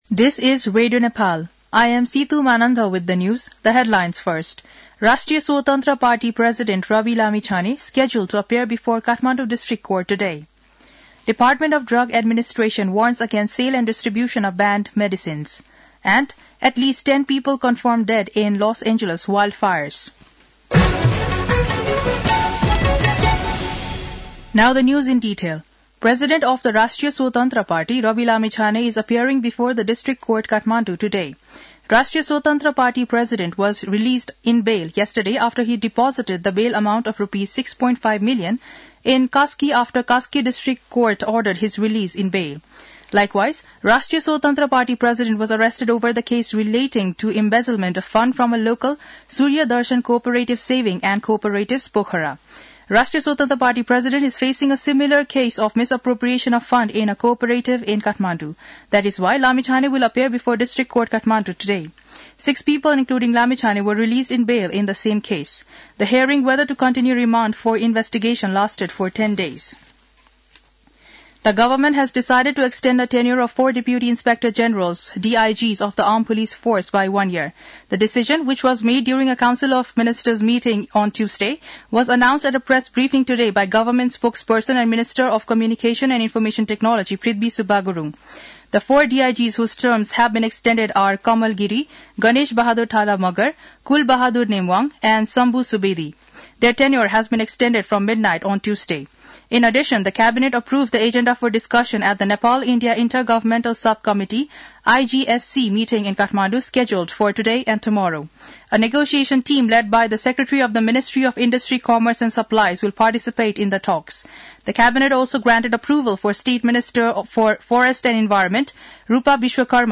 दिउँसो २ बजेको अङ्ग्रेजी समाचार : २७ पुष , २०८१